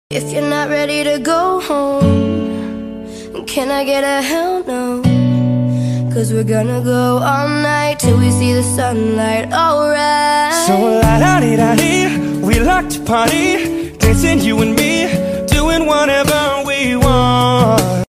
山中靜謐 品茶 書畫 聽雨 山嵐 18 seconds 0 Downloads 台版Harbs 爆量水果千層派！